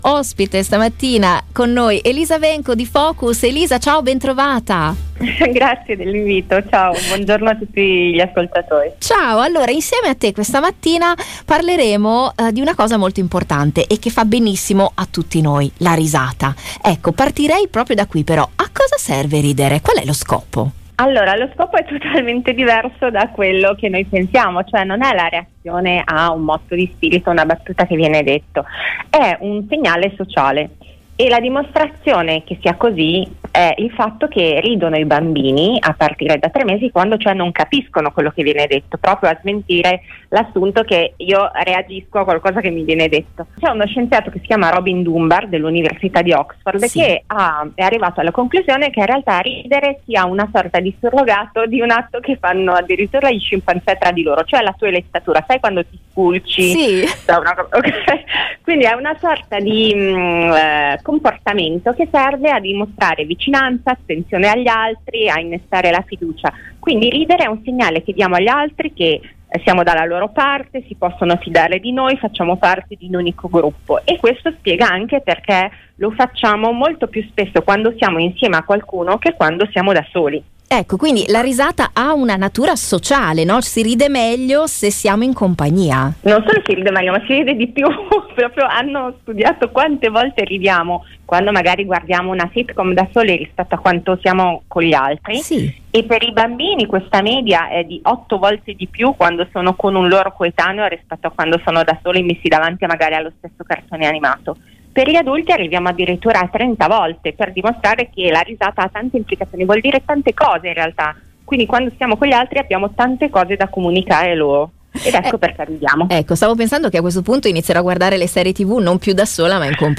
Chi credeva che la risata servisse semplicemente per dimostrare il proprio senso dell’umorismo, rimarrà sorpreso nel sentire questa intervista: